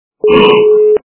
Свинья - Хрюканье свиньи Звук Звуки Свиня - хрюкання
При прослушивании Свинья - Хрюканье свиньи качество понижено и присутствуют гудки.